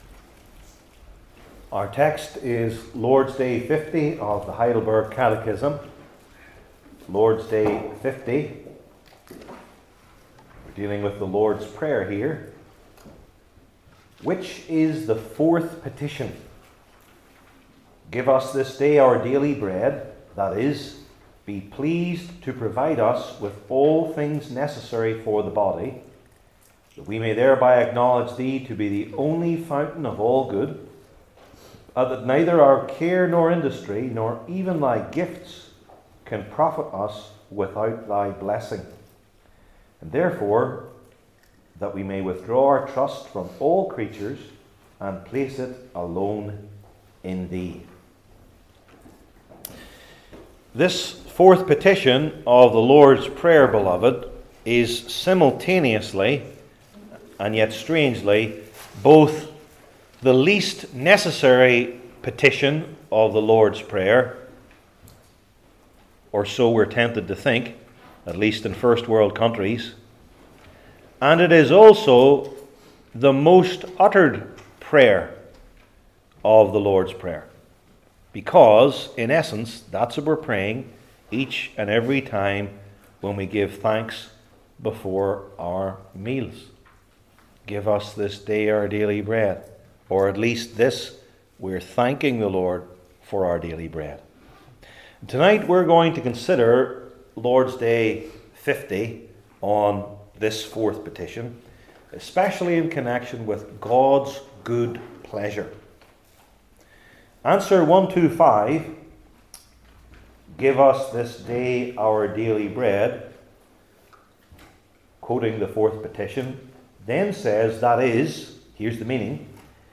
Ephesians 1 Service Type: Heidelberg Catechism Sermons I. God’s Sovereignty II.